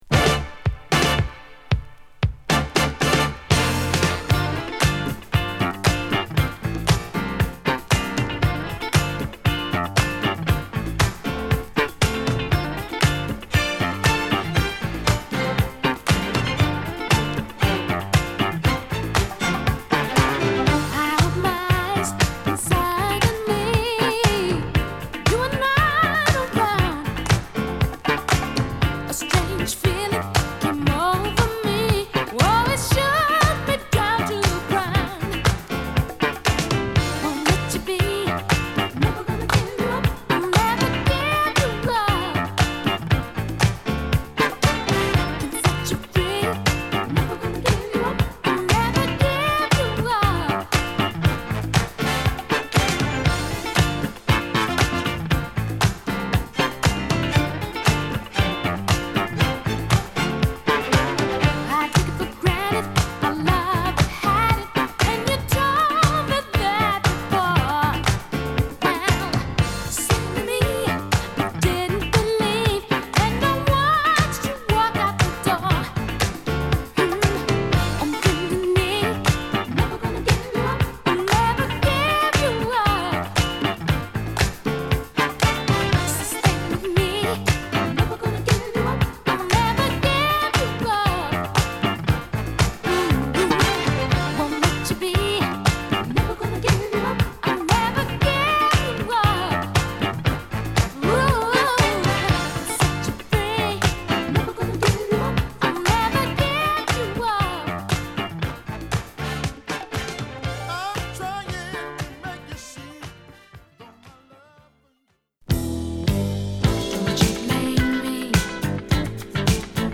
L.A.出身の女性シンガー／ピアニスト